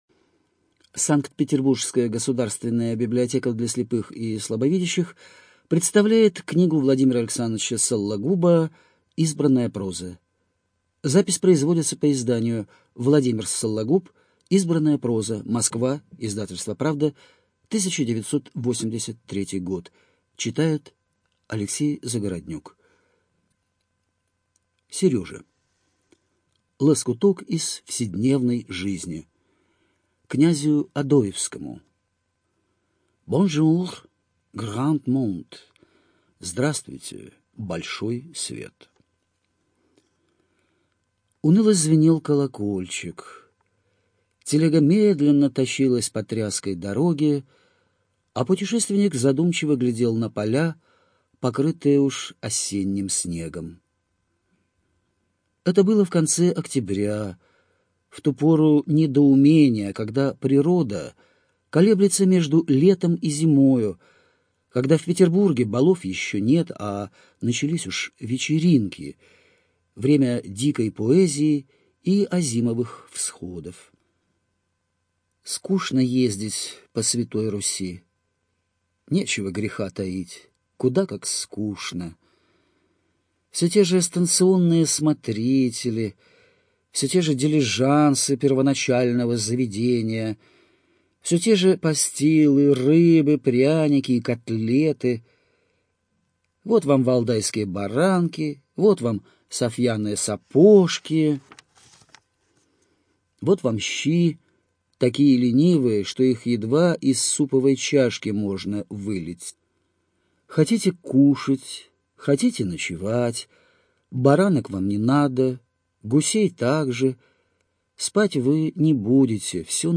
Студия звукозаписиСанкт-Петербургская государственная библиотека для слепых и слабовидящих